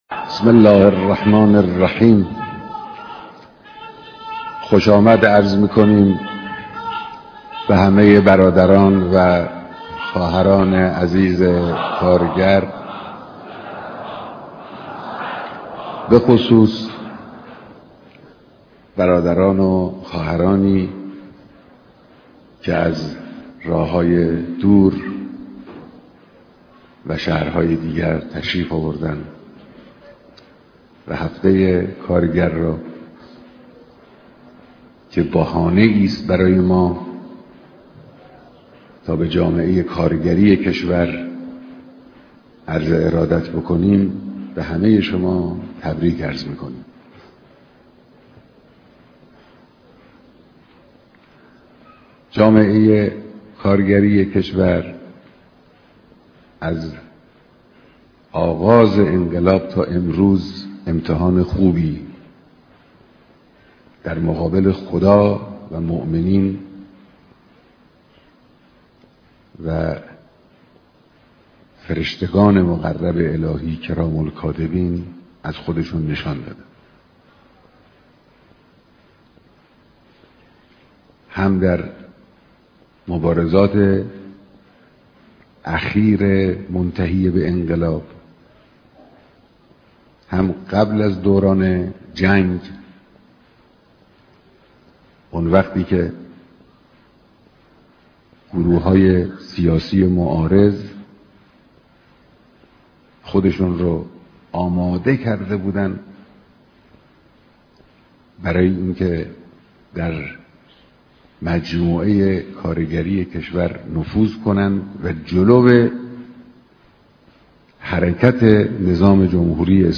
ديدار هزاران نفر از كارگران سراسر كشور